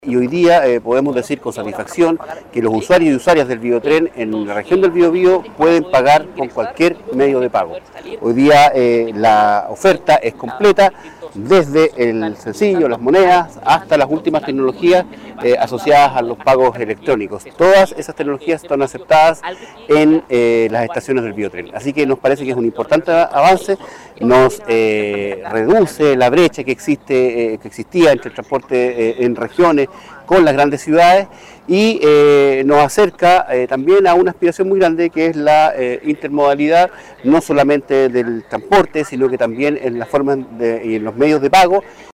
Por su parte, Héctor Silva, seremi de Transporte del Biobío, resaltó que esta opción permite reducir las brechas de conectividad.